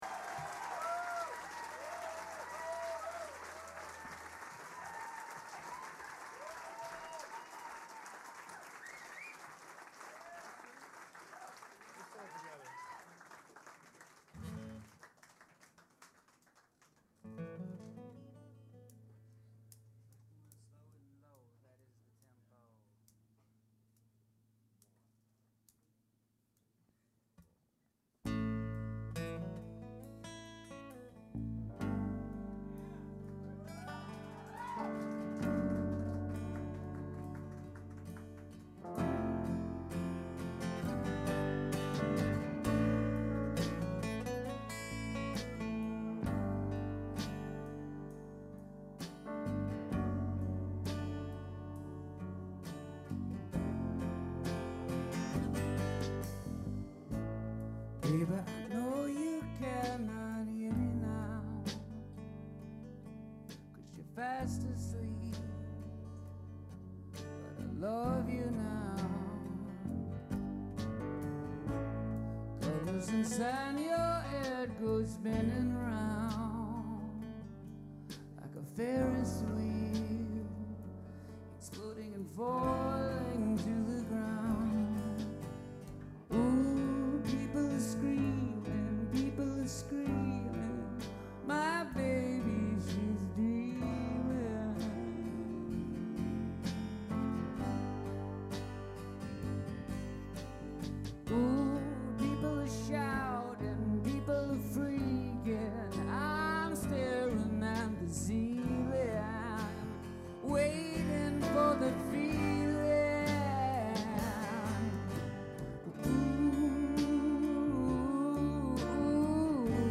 cantante, chitarrista e cantautore classe 1974
File under alternative-country-rock